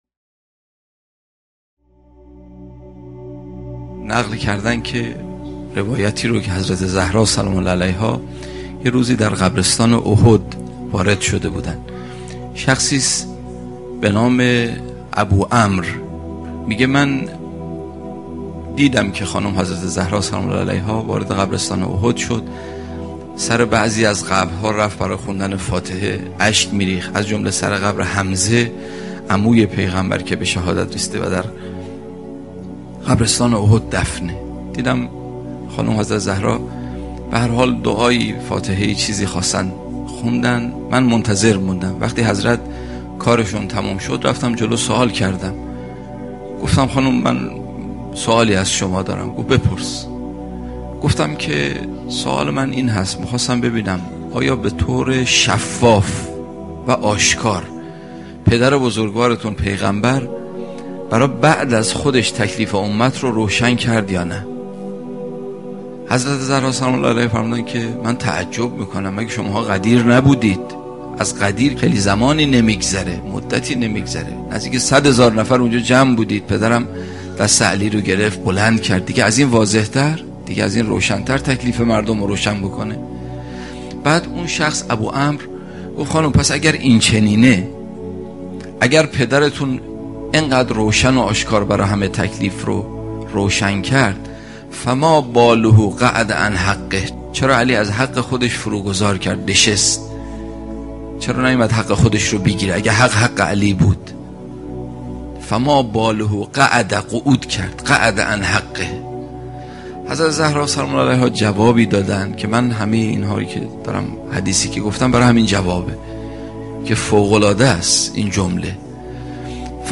سخنرانی بسیار شنیدنی از دفاع حضرت فاطمه(س